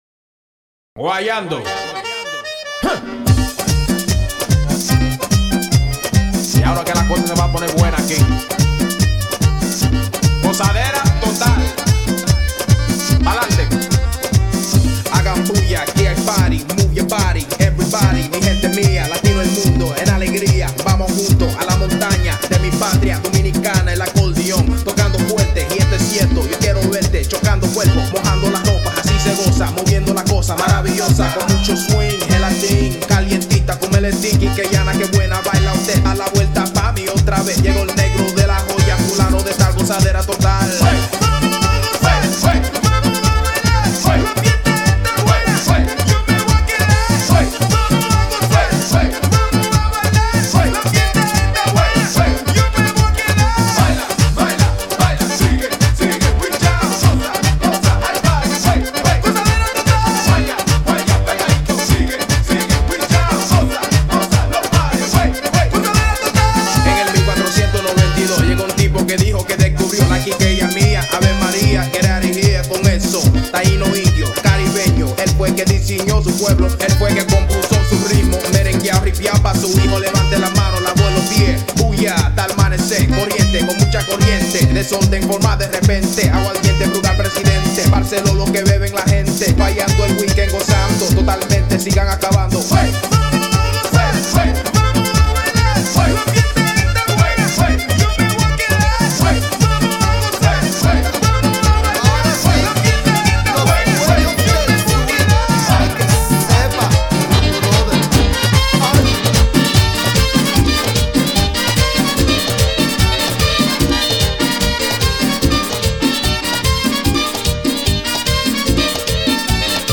A live merengue mix